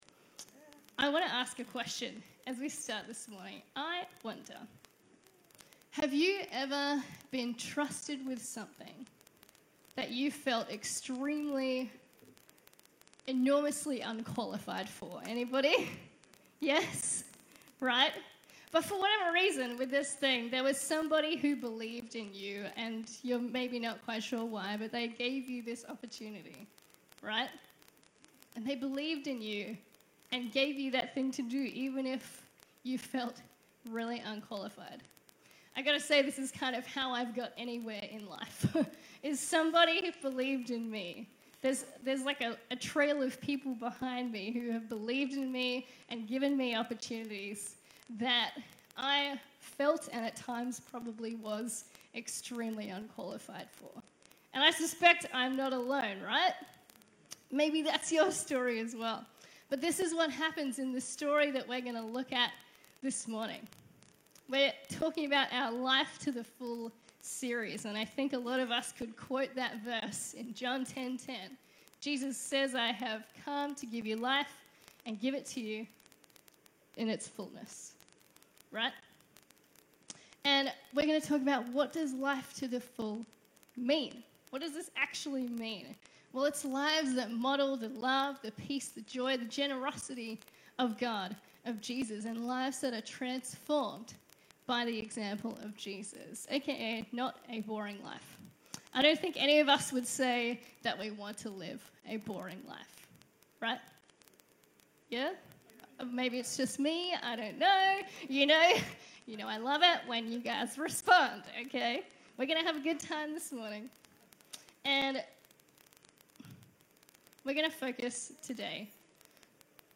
A message from the series "Life to the Full."